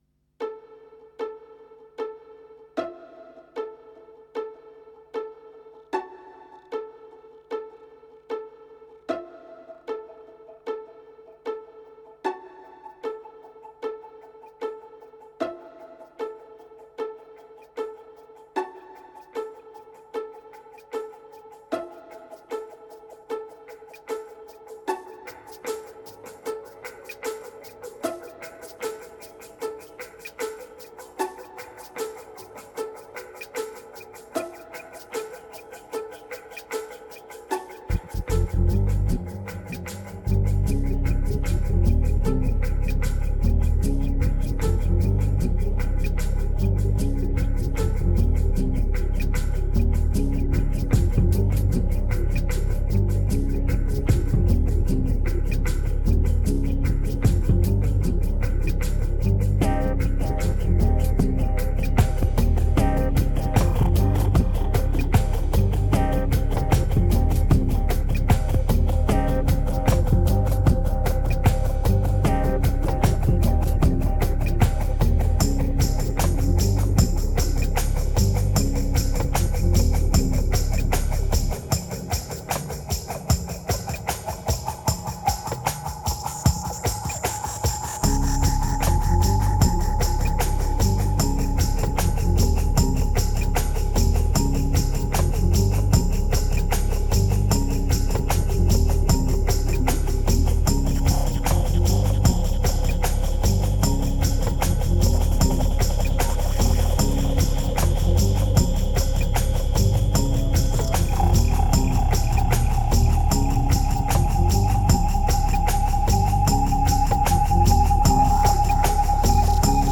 2271📈 - 0%🤔 - 76BPM🔊 - 2011-01-06📅 - -136🌟